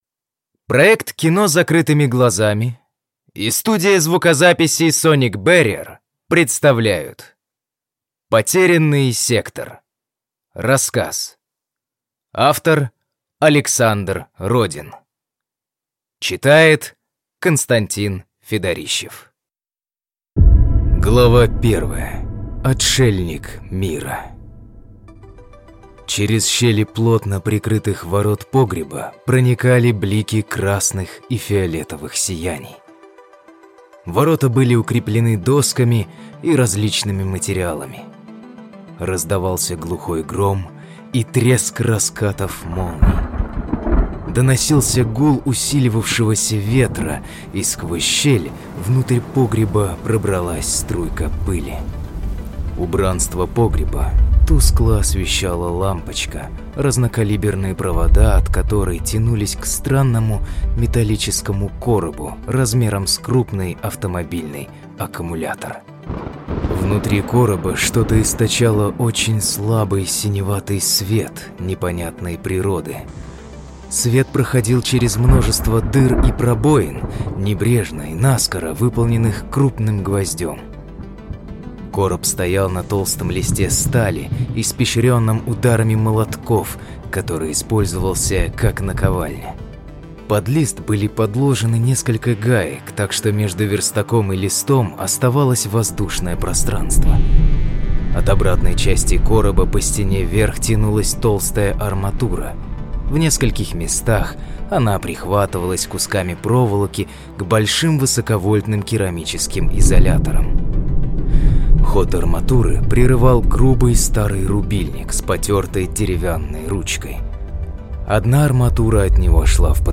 Аудиокнига Потерянный сектор | Библиотека аудиокниг